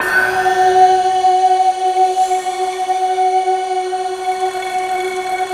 SI1 BAMBO06L.wav